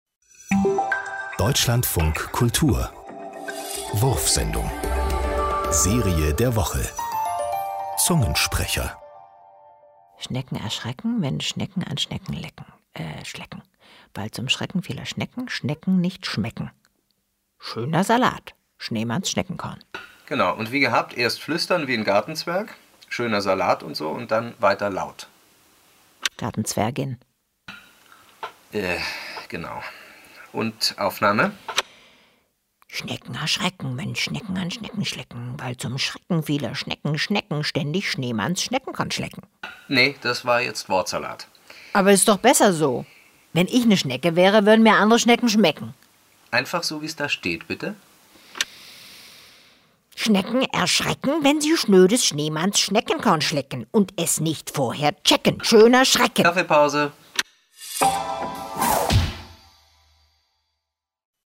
Minihörspiele, die für abwegige Gedanken, neue Wahrnehmungen und intelligenten Humor werben.